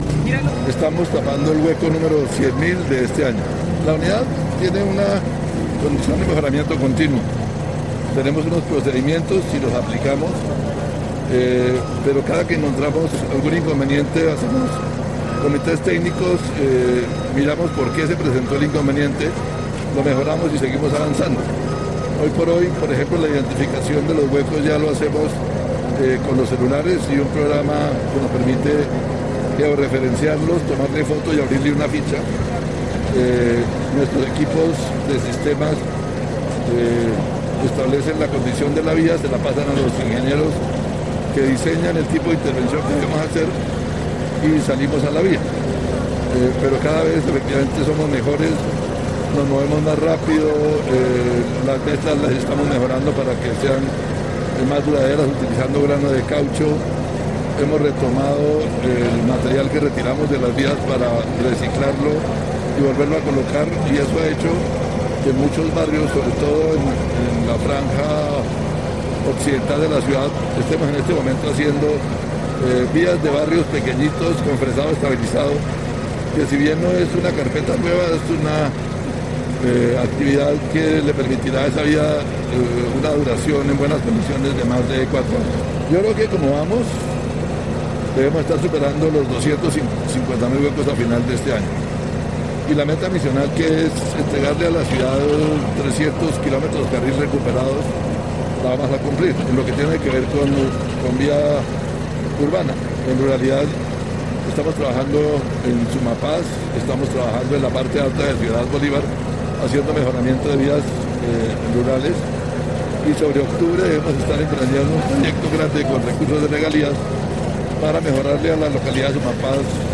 Voz Álvaro Sandoval Reyes director de la UMV